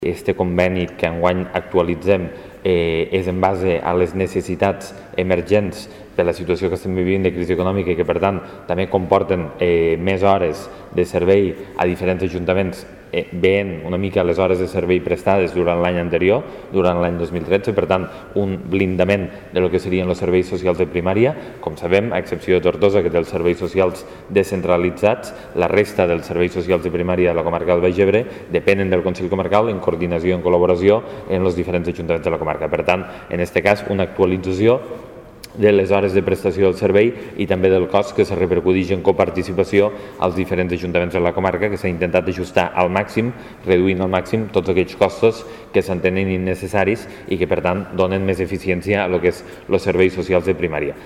(tall de veu) Lluís Soler explica la modificació del conveni de prestació d'atenció bàsica